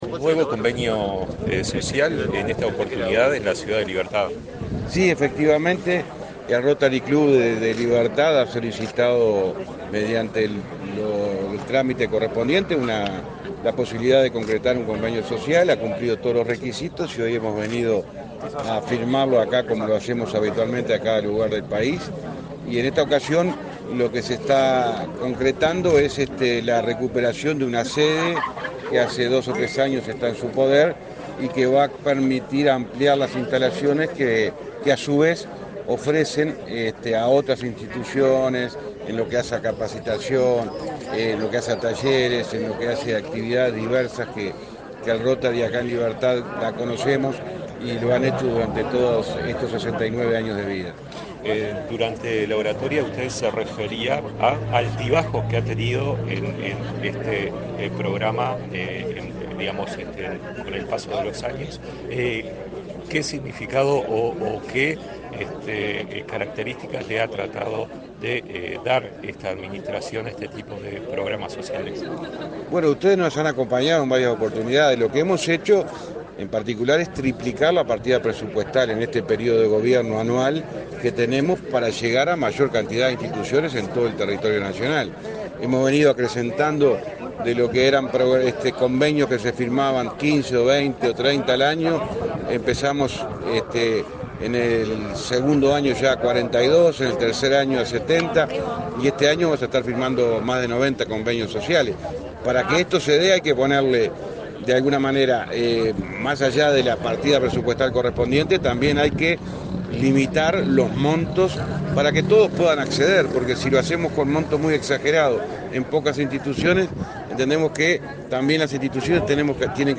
Declaraciones a la prensa del ministro José Luis Falero
Declaraciones a la prensa del ministro José Luis Falero 25/10/2023 Compartir Facebook X Copiar enlace WhatsApp LinkedIn El Ministerio de Transporte y Obras Públicas (MTOP) firmó, este 25 de octubre, convenio en el Rotary Club en la ciudad de Libertad, en el departamento de San José. Tras el evento, el ministro José Luis Falero realizó declaraciones a la prensa.